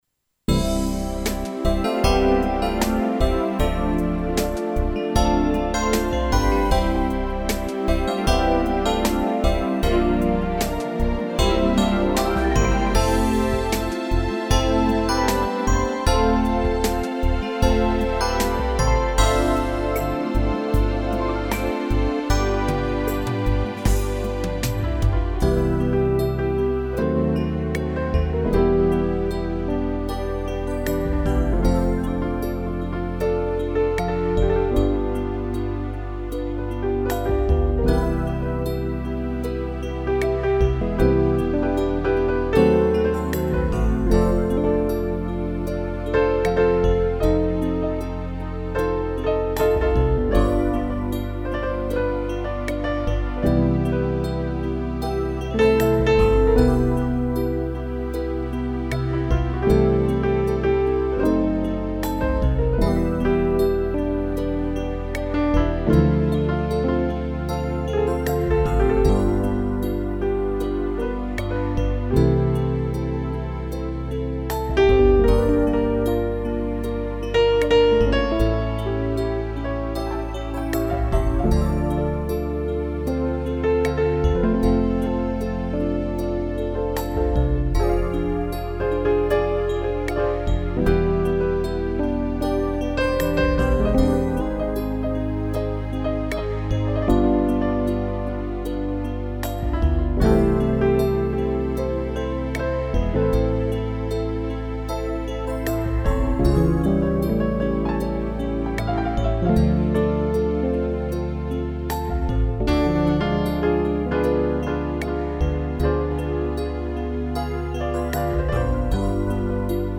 instrumental
arranho e interpretação teclado